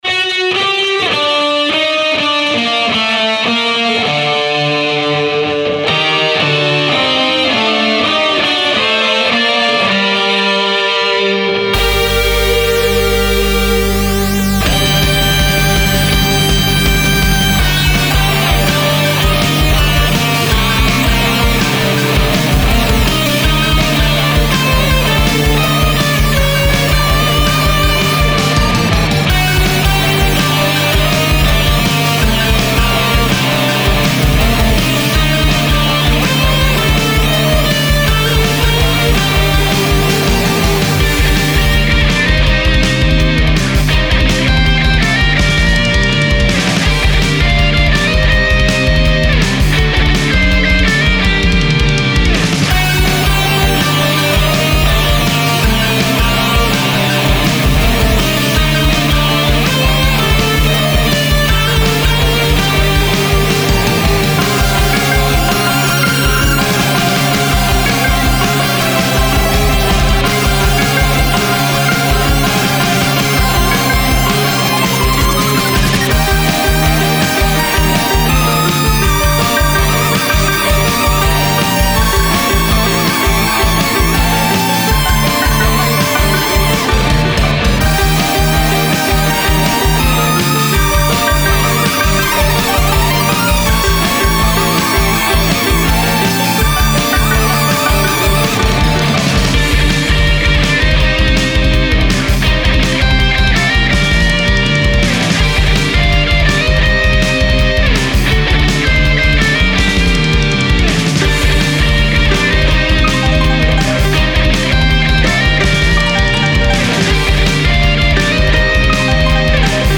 Arrangement | Remix